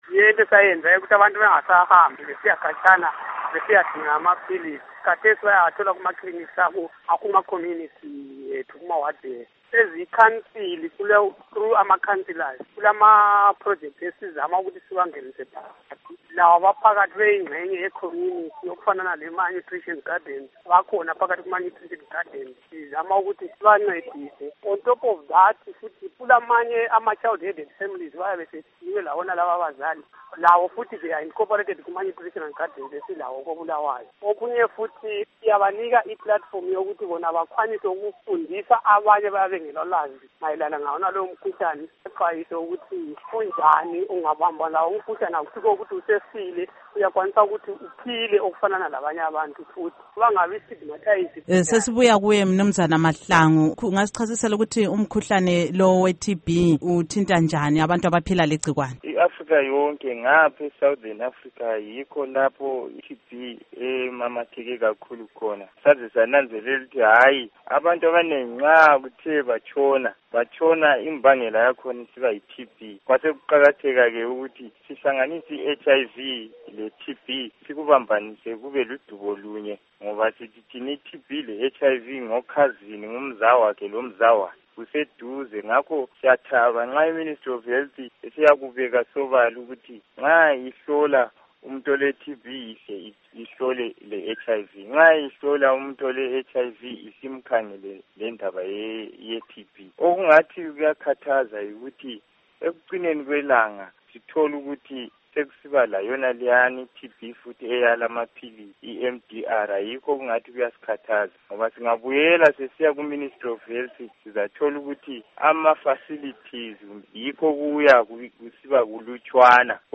Ingxoxo LoKhansila Ernest Rafa Moyo Endawonye LoMnu.